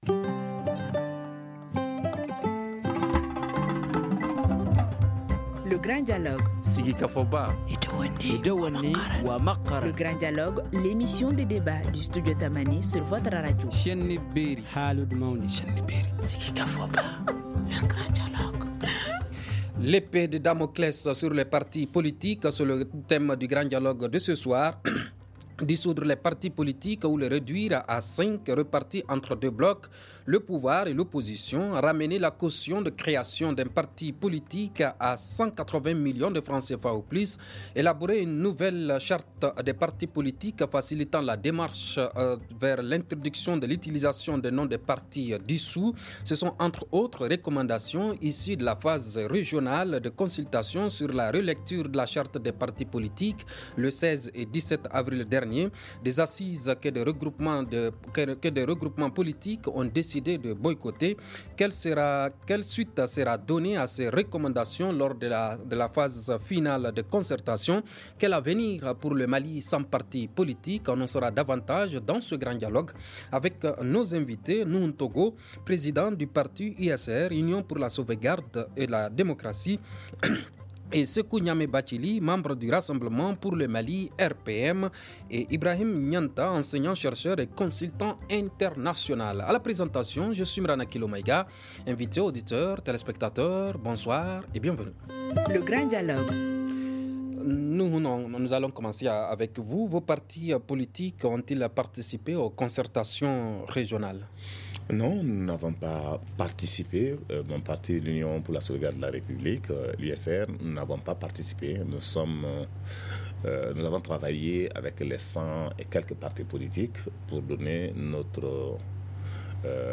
On en saura davantage dans ce Grand Dialogue avec nos invités :